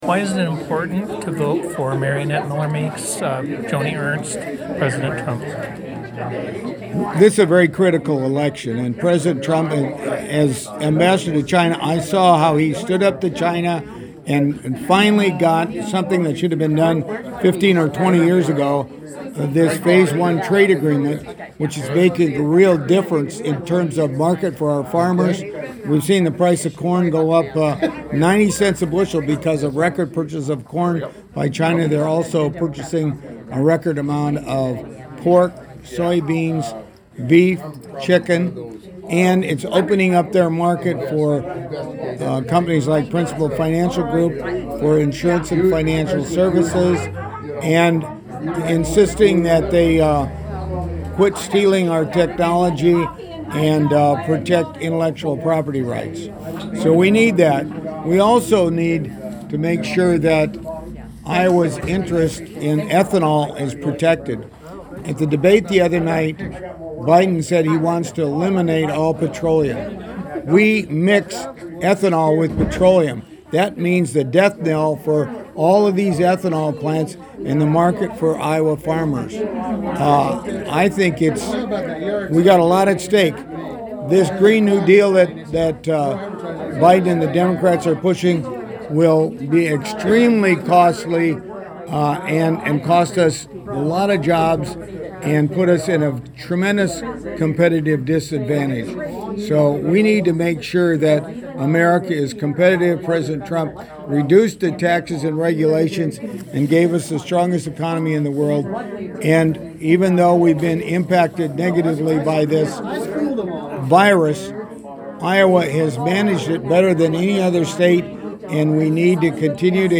Mariannette Miller-Meeks interview:
Terry Branstad interview: